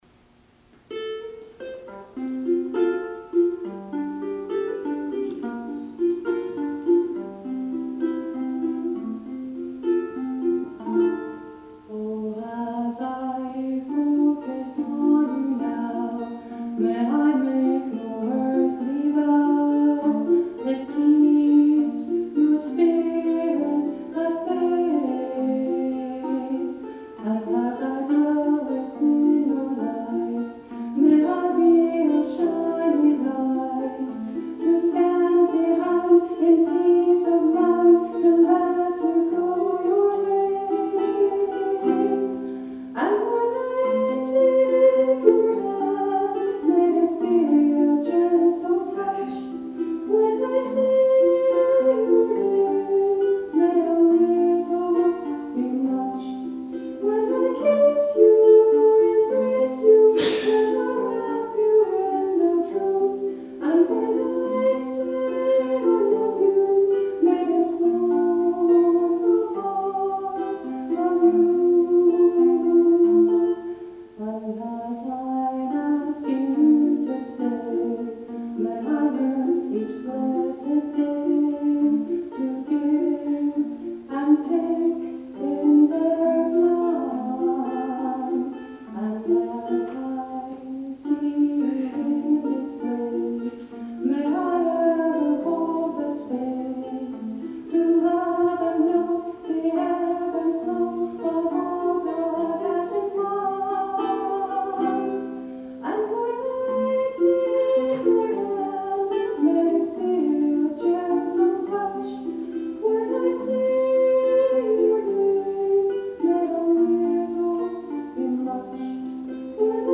During our wedding ceremony, I played two original songs.
In the audio player above, you’ll here the live recordings from our wedding ceremony.